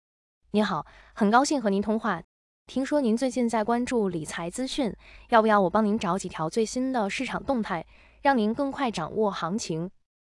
TTS语音合成
这一代 TTS 不再是“机器发声”，而是可以真正传递情感和温度的 AI 声音，在自然度、韵律、口气、情绪、语气词表达等方面全面突破，让听者几乎无法分辨“人声”与“机器声”。